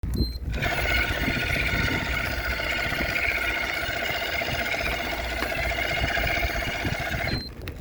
A few weeks ago my Shimano e7000 motor started making a weird noise (mp3 attached) as soon as I applied force to the pedals.
After just a few seconds, system would beep twice and shutdown.